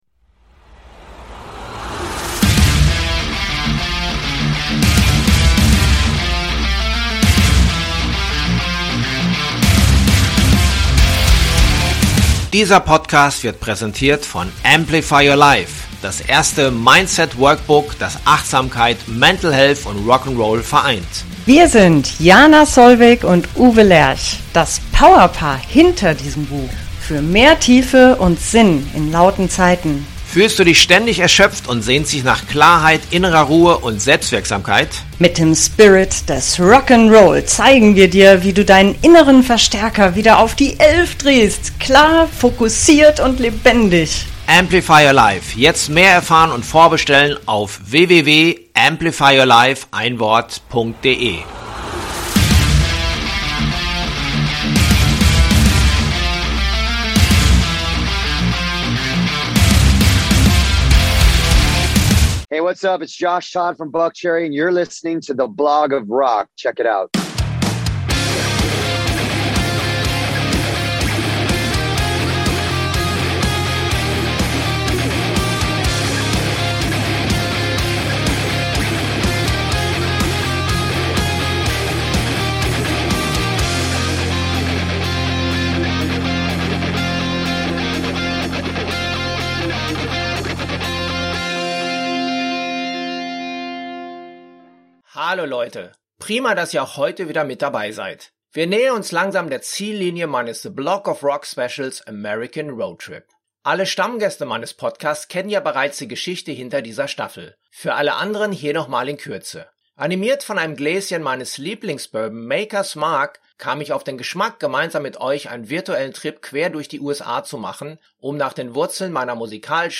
Unser heutiger Gast empfängt uns in seinem Tourbus: JOSH TODD: eine richtige Rock’n’Roll Rampensau. Tätowiert bis unter die Unterlippe hält er seit über die 20 Jahren die Fahnen seiner Band BUCKCHERRY aus Los Angeles hoch, die sicher mehr Tiefen als Höhen erlebt hat.